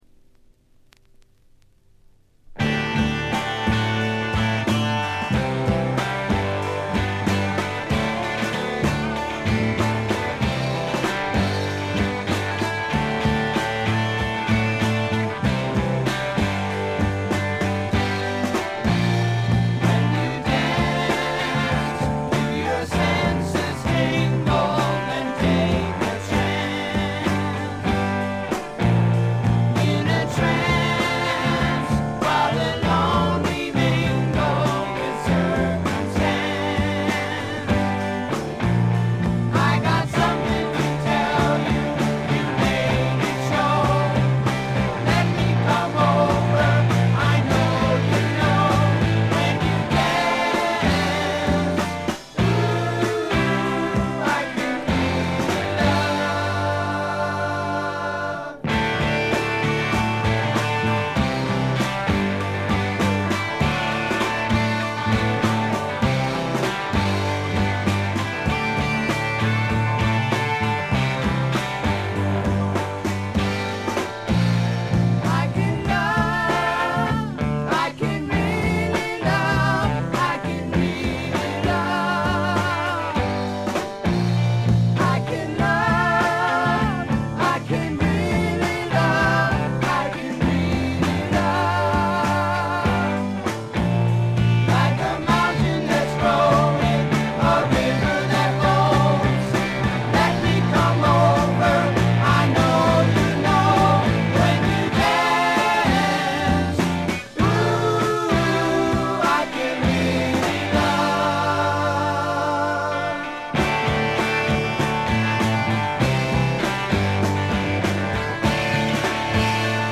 試聴曲は現品からの取り込み音源です。
guitar, vocal
piano
bass